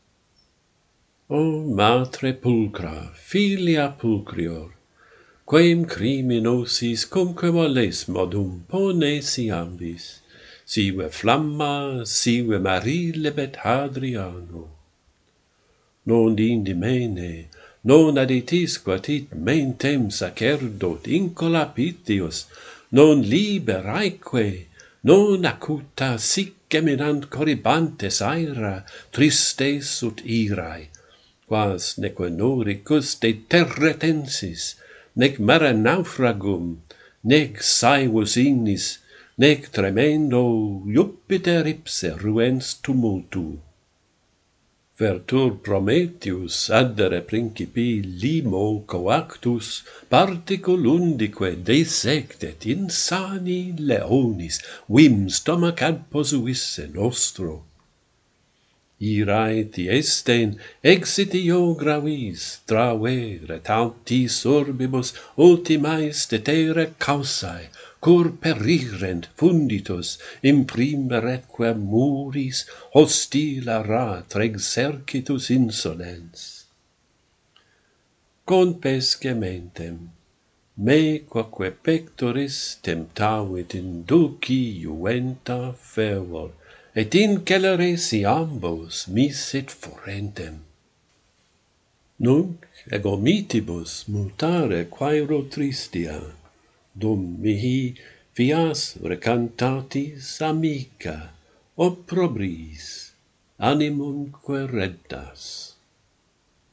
Lovely mother, lovelier daughter - Pantheon Poets | Latin Poetry Recited and Translated